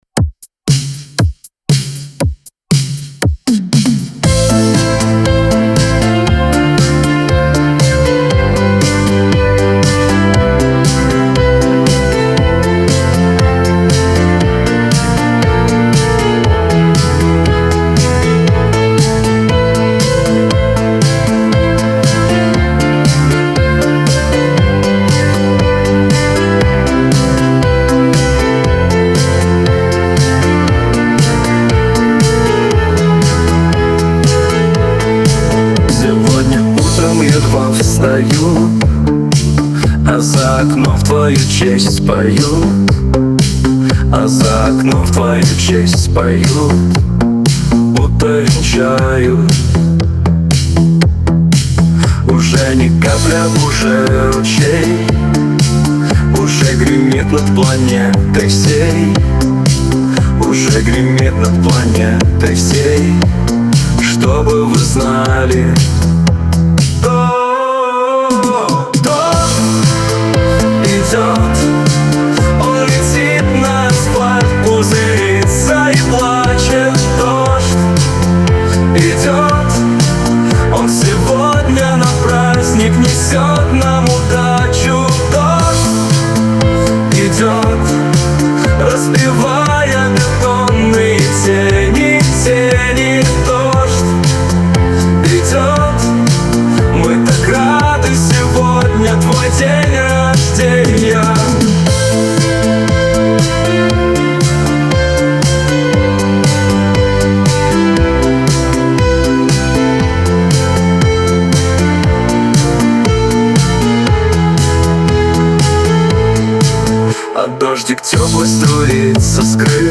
Русская музыка